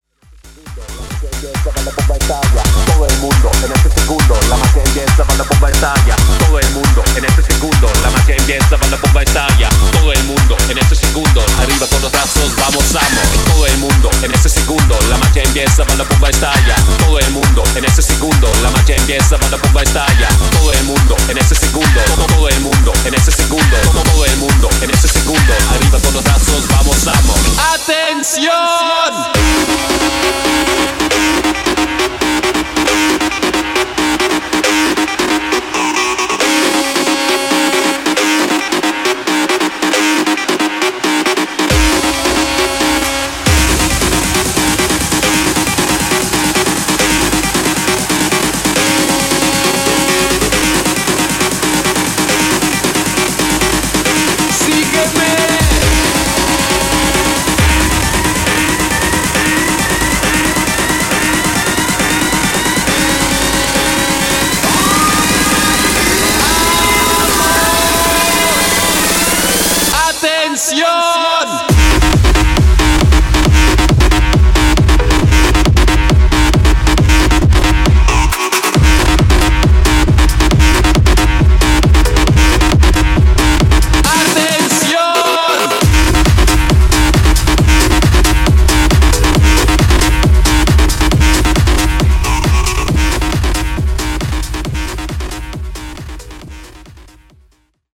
Festival Mashup Edit)Date Added